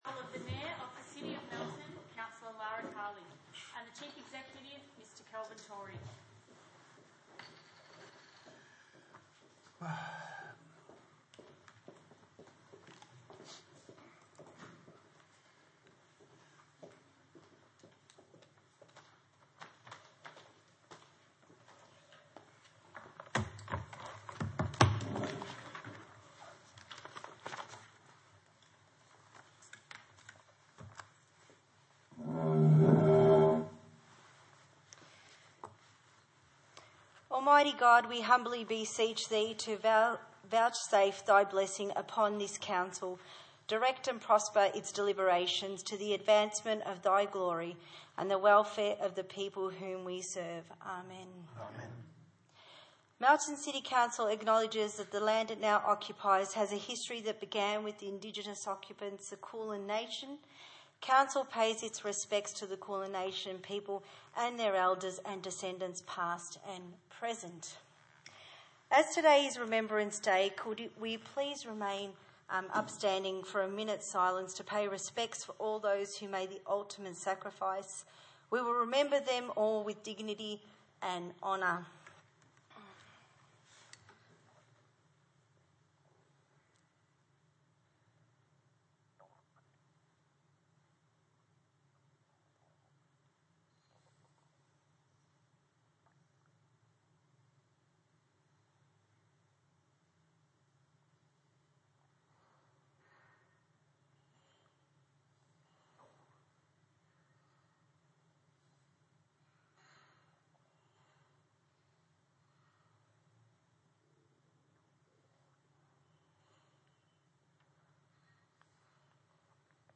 Ordinary Meeting 11 November 2019
Burnside Community Hall, 23 Lexington Drive, Burnside View Map